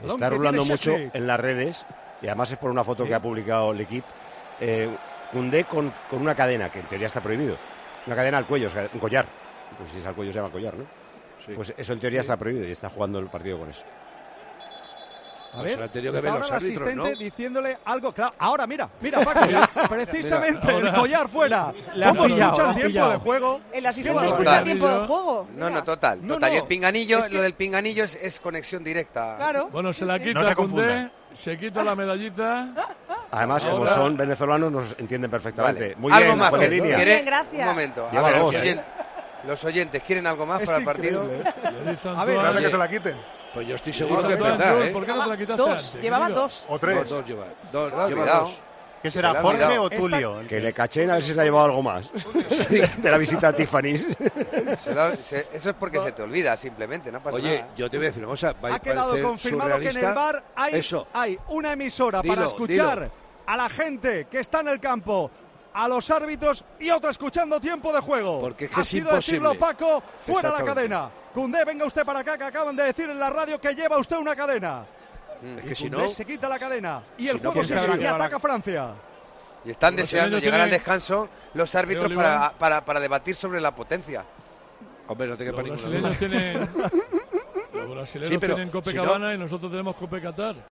Paco González informa que Koundé está jugando con una cadena y segundos después el árbitro se la manda quitar